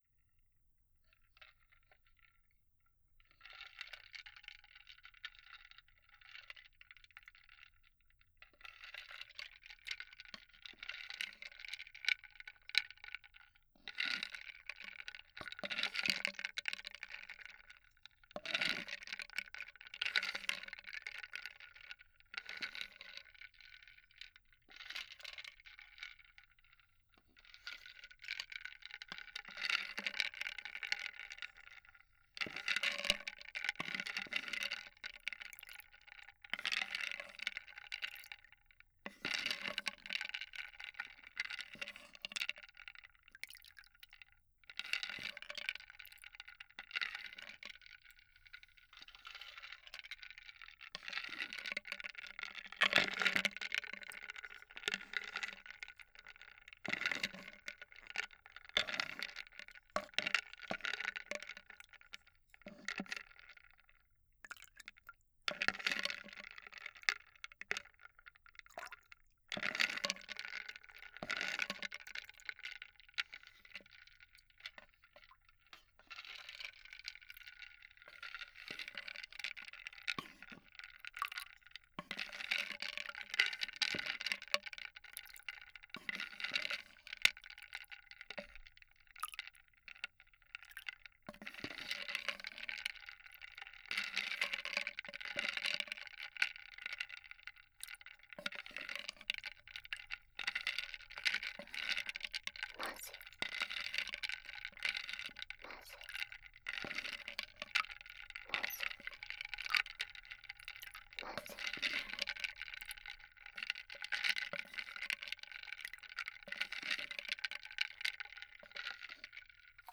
02.水音パートのみ.wav